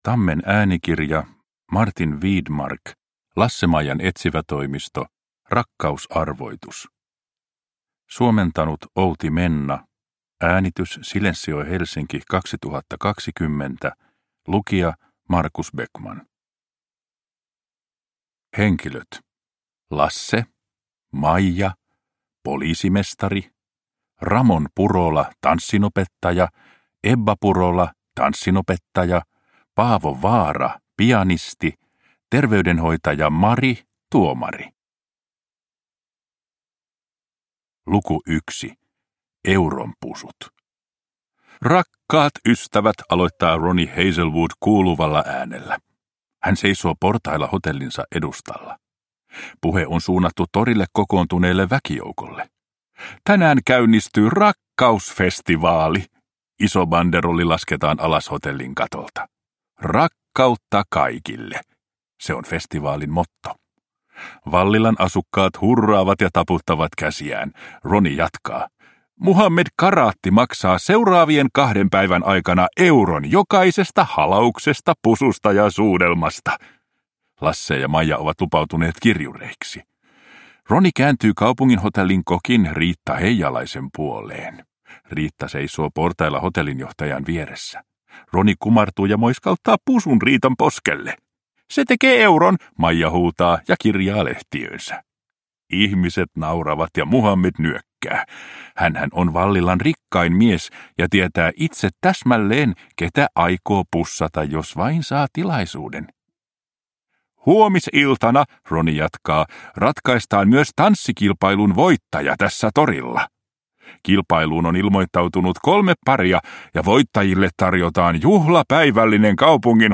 Rakkausarvoitus. Lasse-Maijan etsivätoimisto – Ljudbok